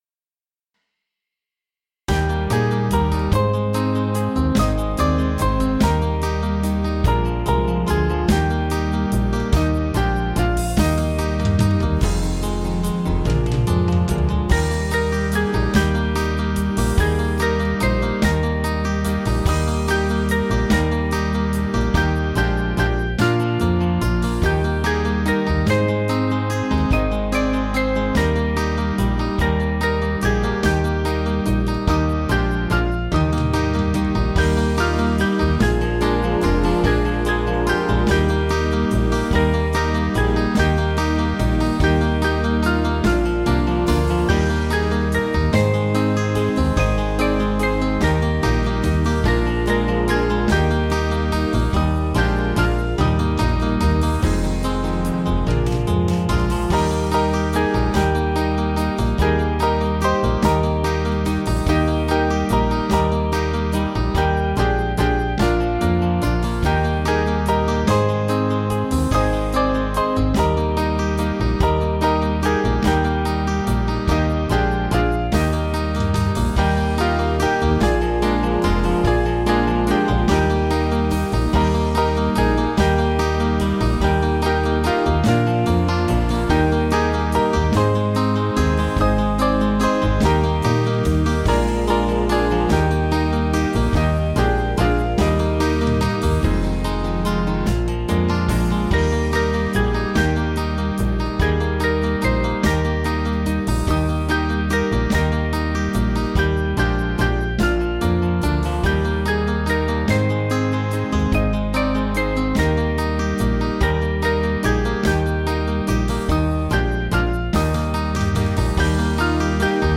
Small Band
(CM)   4/Eb 482.3kb